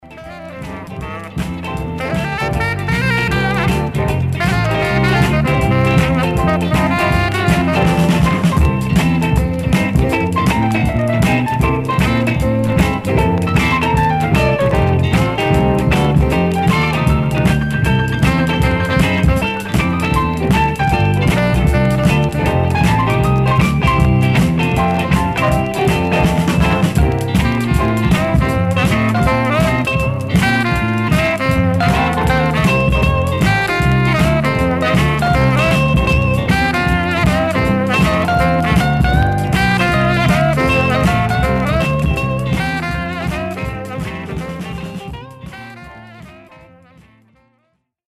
Some surface noise/wear Stereo/mono Mono
Rythm and Blues